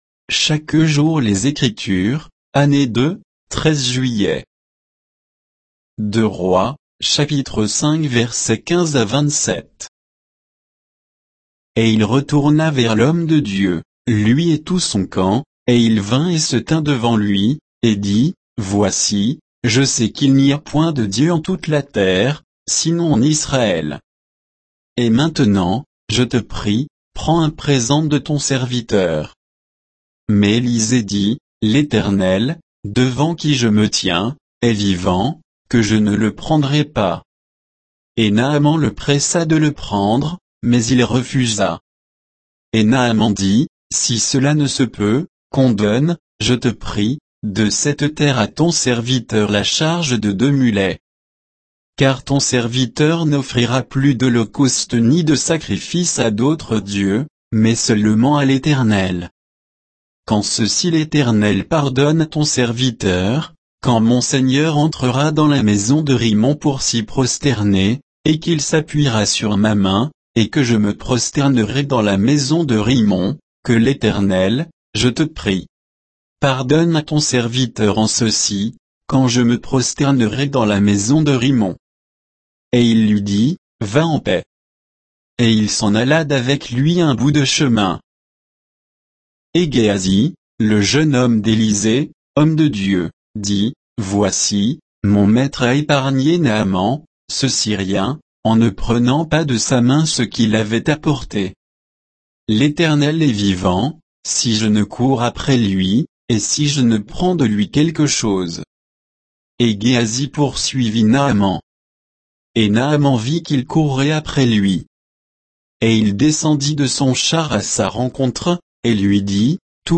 Méditation quoditienne de Chaque jour les Écritures sur 2 Rois 5